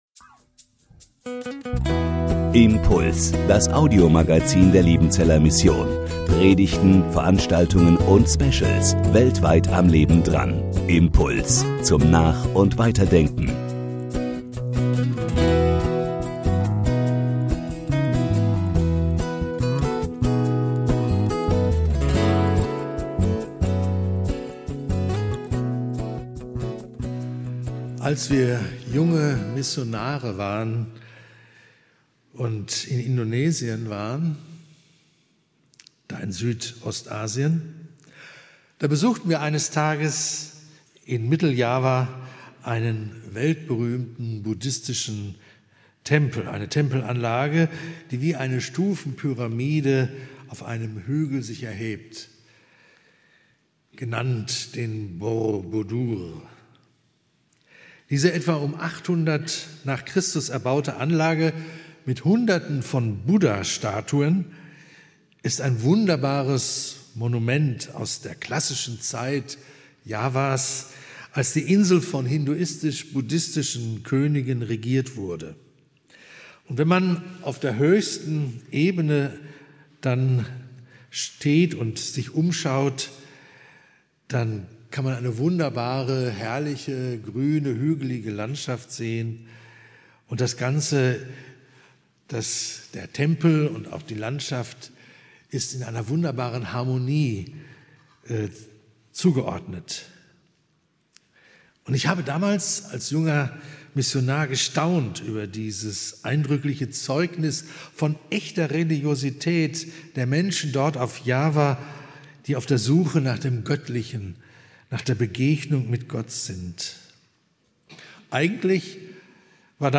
aus dem MBG-Gottesdienst der Missionsberg-Gemeinde (LM)
Predigt